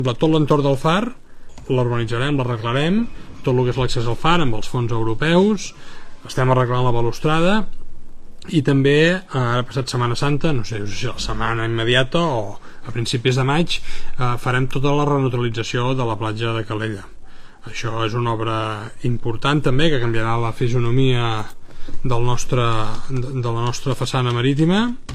Després de Setmana Santa s’iniciaran les obres de dos projectes subvencionats pels fons europeus NEXT GENERATION, tal com va anunciar l’alcalde Marc Buch, ahir en el directe que va fer a Instagram on respon a les preguntes de les persones que s’hi connecten.